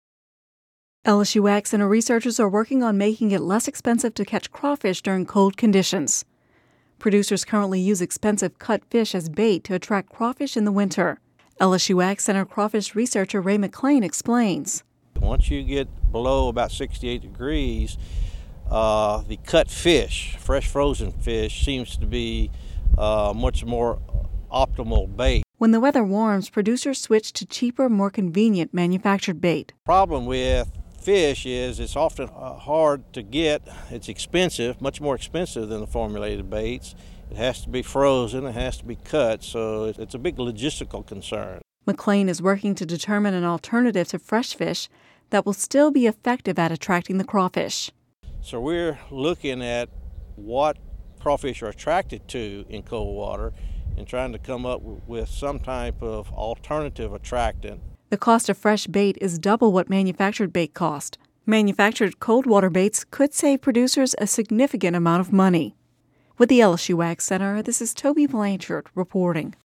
(Radio News 03/14/11) LSU AgCenter researchers are working on making it less expensive to catch crawfish during cold conditions. Producers currently use expensive cut fish as bait to attract crawfish during the winter.